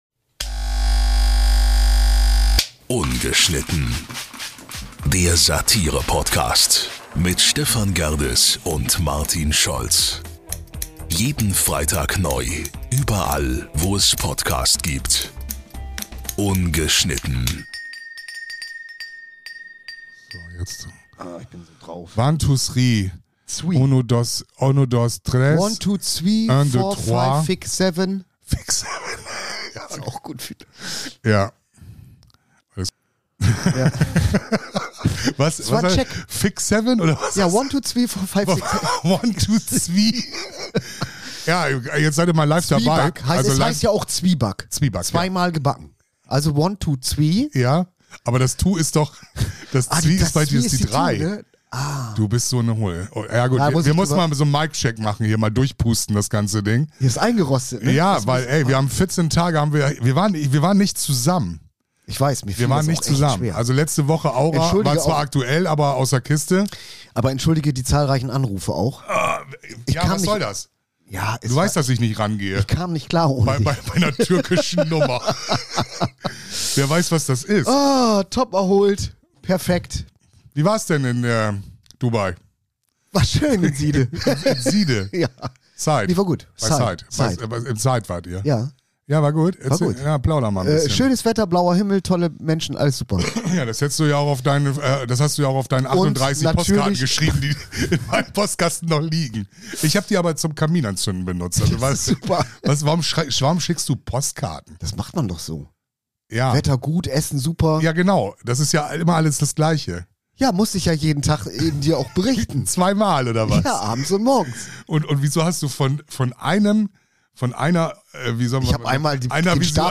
#135 Sprechkäse ~ Ungeschnitten
Wir sind wie ein 45 Minuten Trip, Euer Rausch, frei sein, sich frei fühlen, nicht denken, nicht grübeln, keine Sorgen.